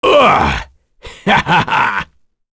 Phillop-Vox_Victory.wav